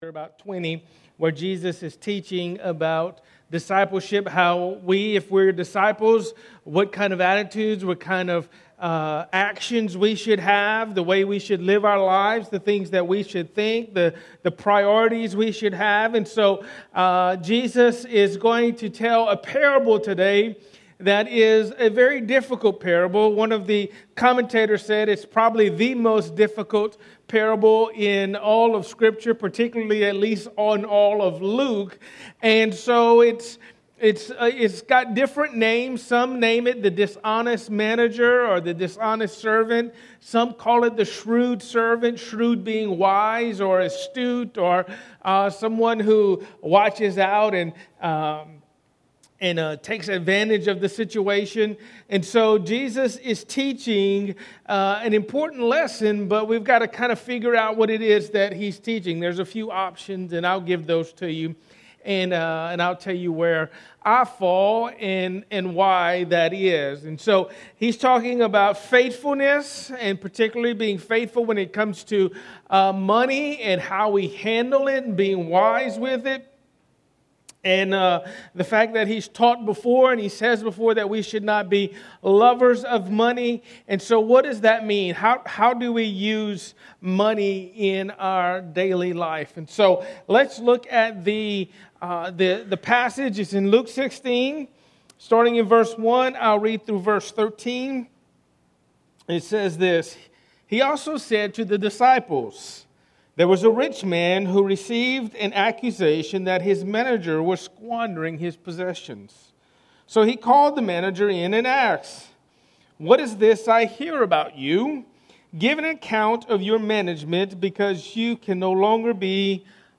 A message from the series "Luke."